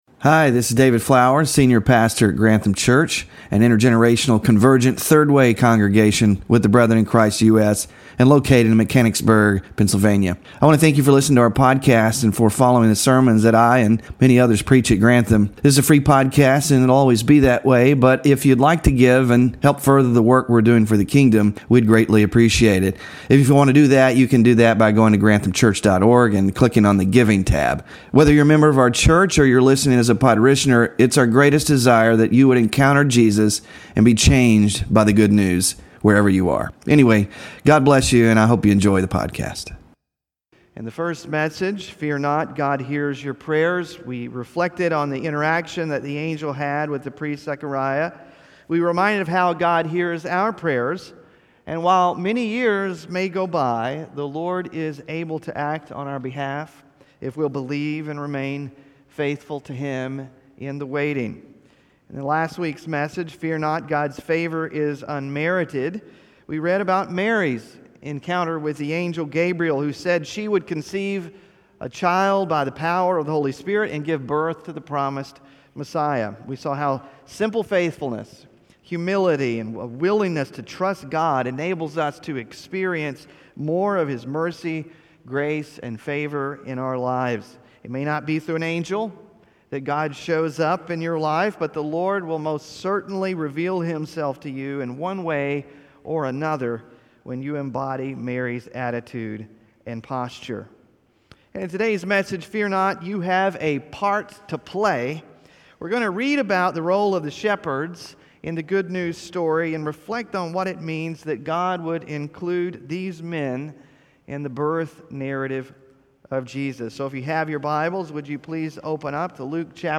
The announcement of Jesus’ birth to shepherds is quite significant when you stop to consider its implications and what it says about God and the part we all play in his plan. In the fourth Sunday of Advent